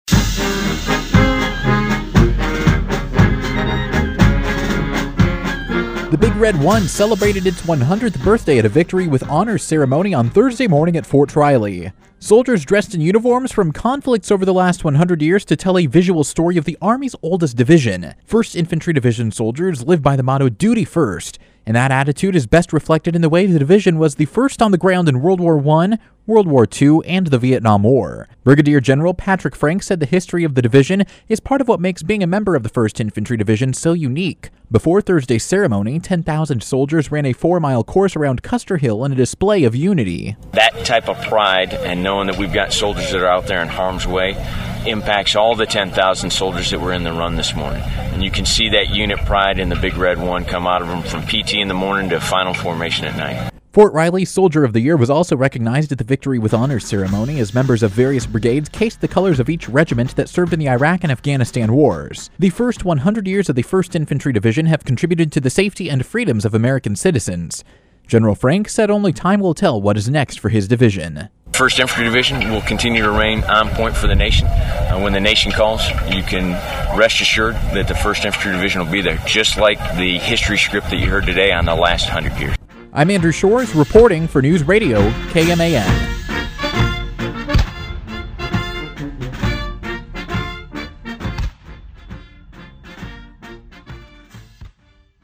FORT RILEY — The oldest division in the United States Army recognized its 100th birthday at a Victory with Honors Ceremony on post Thursday morning.
Brig. Gen. Patrick D. Frank addressed an audience at the First Infantry Division Headquarters Thursday morning.